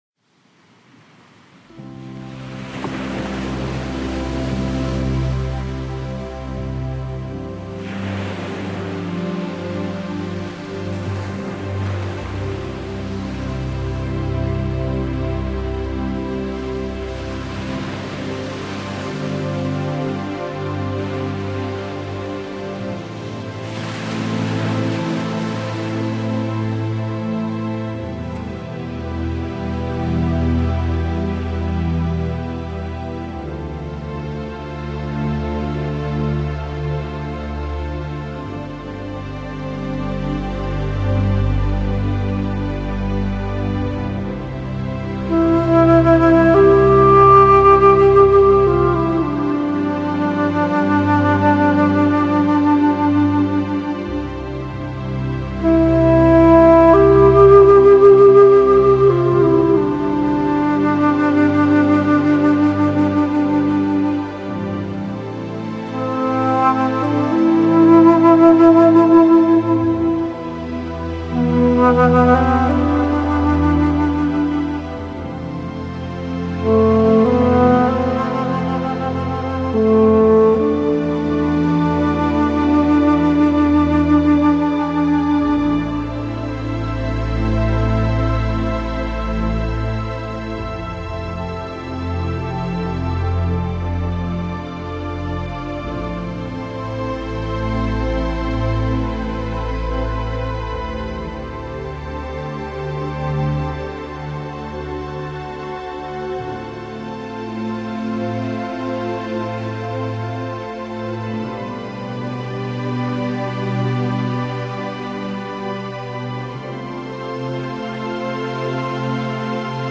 熟悉的旋律重温回忆中的浪漫，悠扬的音符盘旋在寂静的空中。